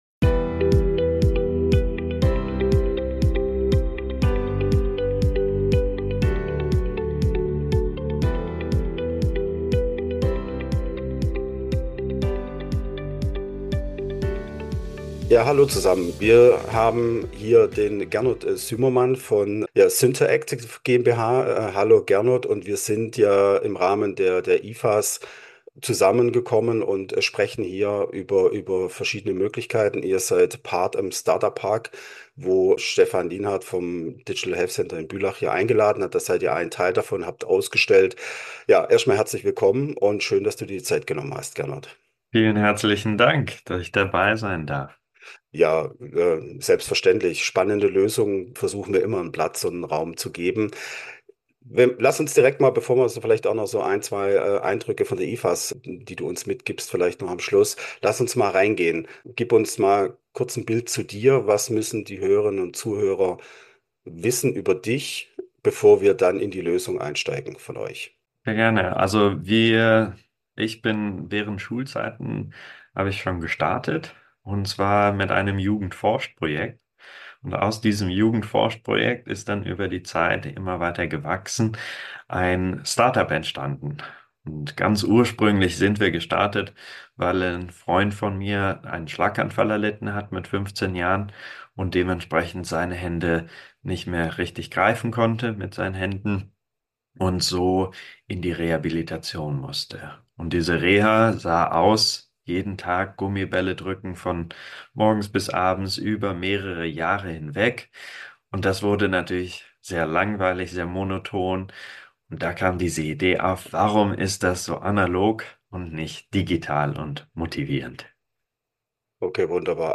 Wir waren mit Rocketing Healthcare unterwegs auf der IFAS 2024 in Zürich.